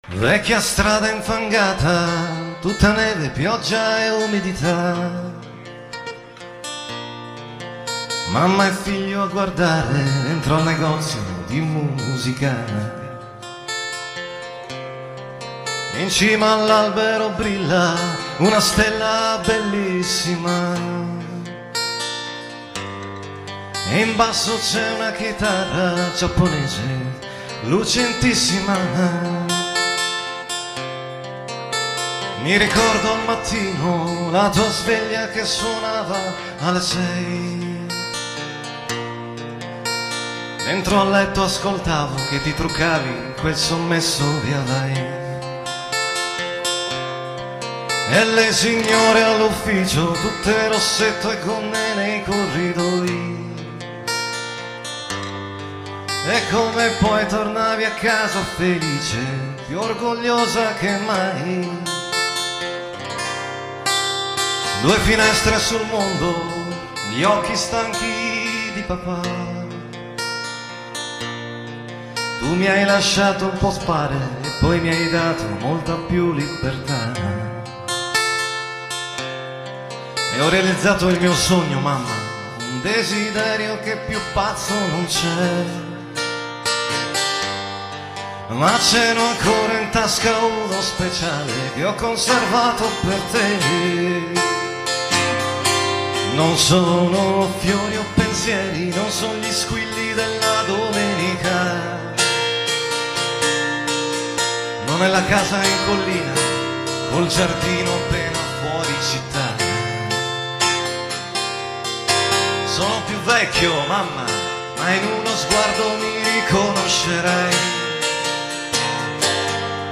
ROMA, "Radio Londra Caffè" (futuro ConteStaccio)